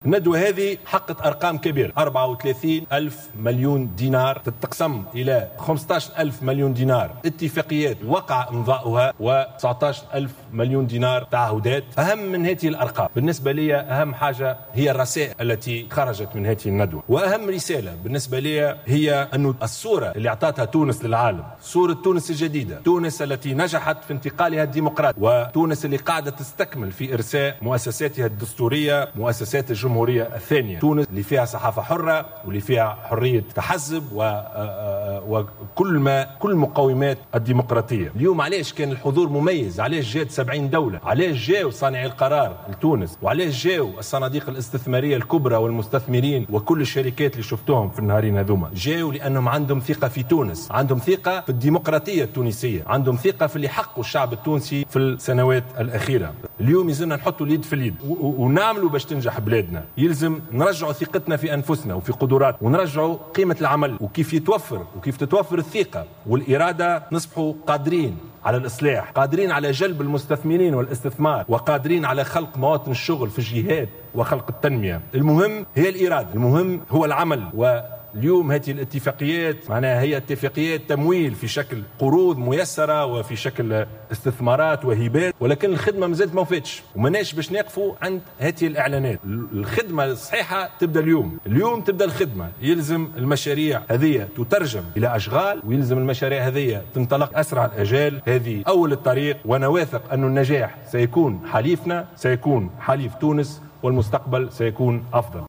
وأضاف في ندوة عقدها بمناسبة اختتام الندوة الدولية للاستثمار التي احتضنتها تونس على امتدا يومي 29 و30 نوفمبر، ان الندوة شهدت مشاركة صناديق استثمارية دولية مهمة.